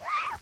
wash2.ogg